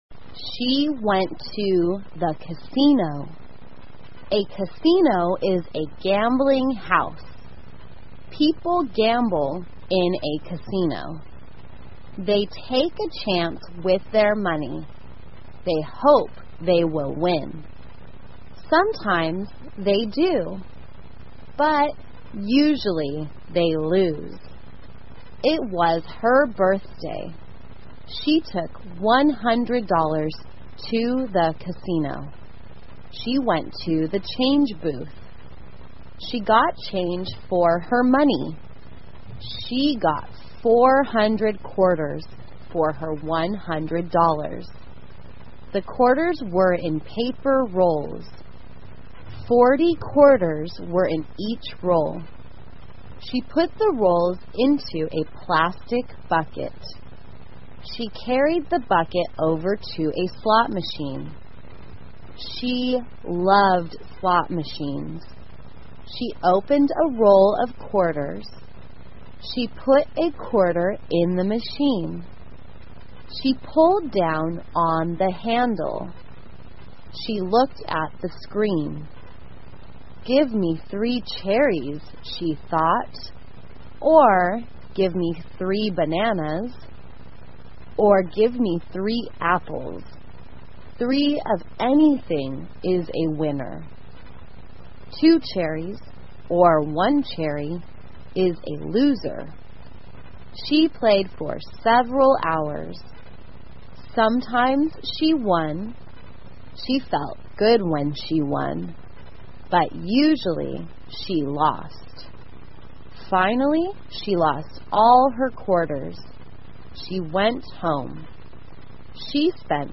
慢速英语短文听力 赌场 听力文件下载—在线英语听力室